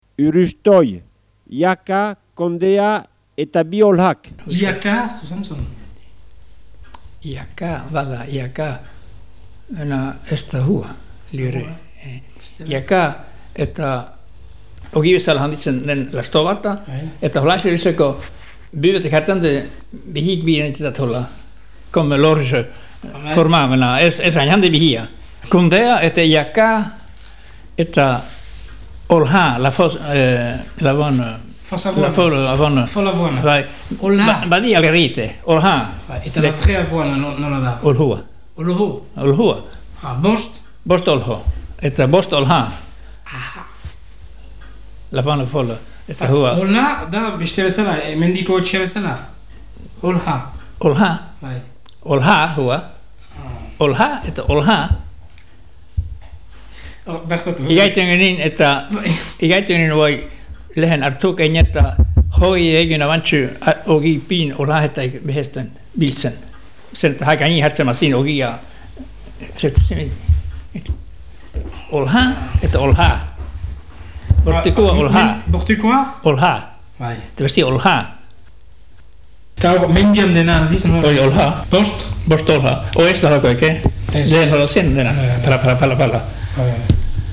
Bi hitzek, haatik, ez dute ahoskera bera, lekukoak adierazten duenaren arabera.